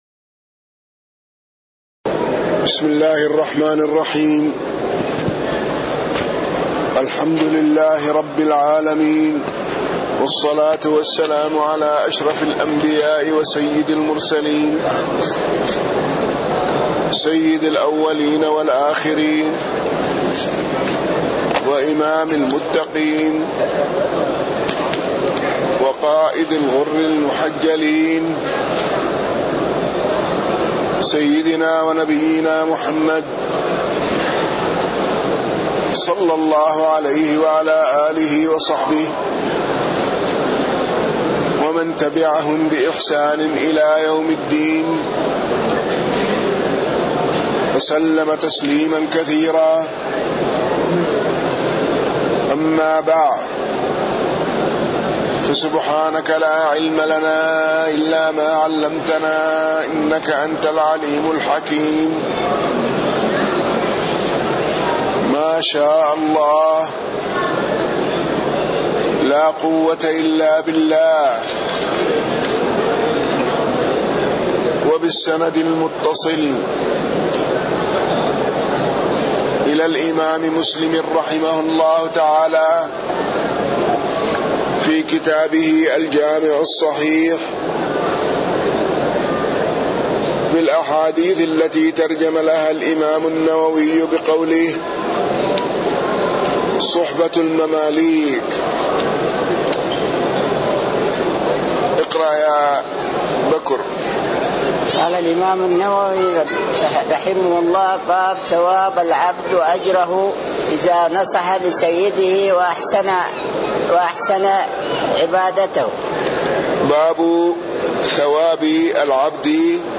تاريخ النشر ١٨ جمادى الأولى ١٤٣٥ هـ المكان: المسجد النبوي الشيخ